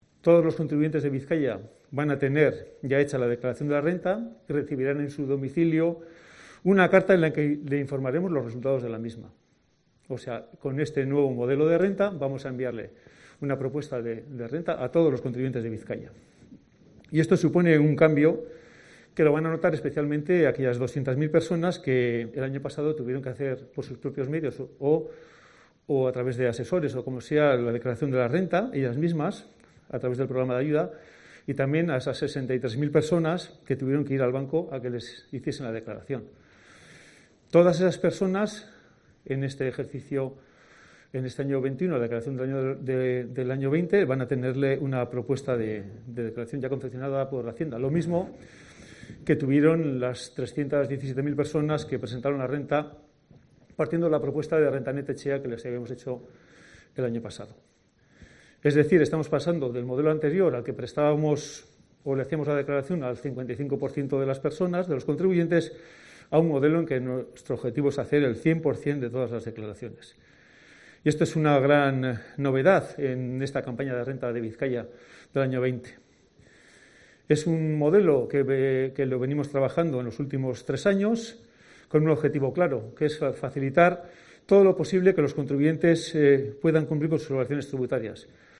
José María Iruarrizaga, diputado de Hacienda y Finanzas